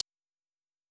Closed Hats
Hi-Hat (Everything We Need).wav